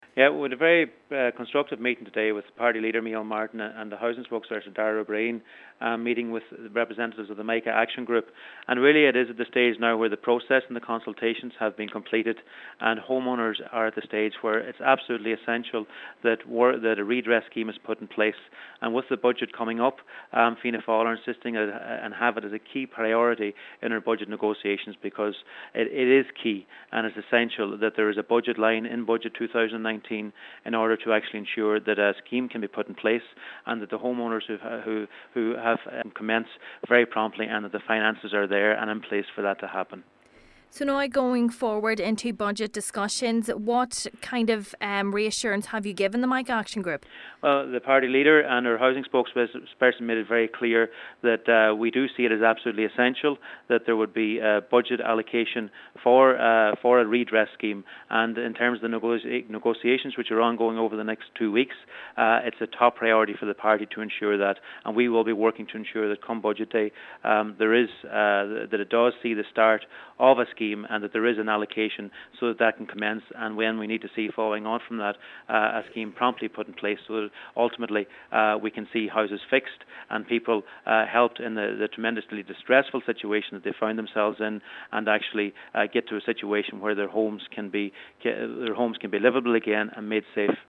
Deputy Charlie McConalogue says it is vital a provision for homeowners affected by the defective block issue is made in the upcoming budget: